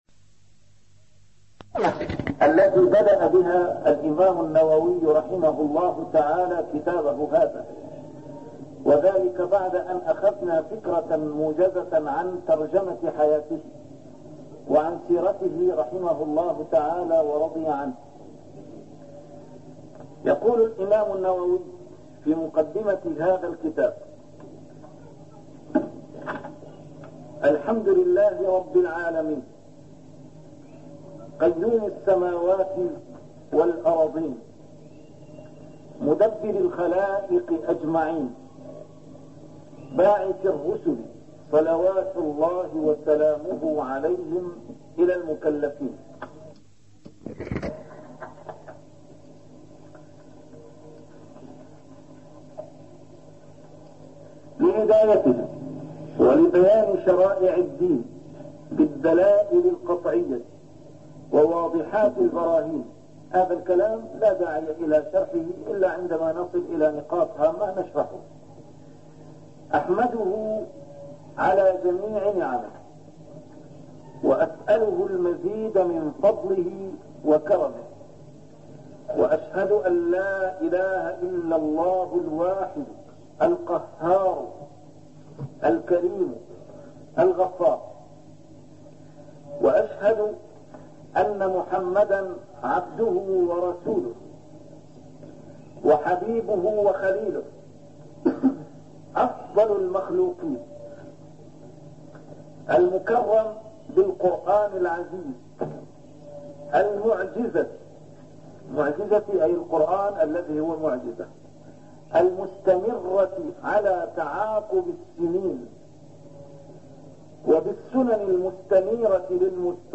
A MARTYR SCHOLAR: IMAM MUHAMMAD SAEED RAMADAN AL-BOUTI - الدروس العلمية - شرح الأحاديث الأربعين النووية - مقدمة الإمام النووي + شرح الحديث الأول (إنما الأعمال بالنيات) 2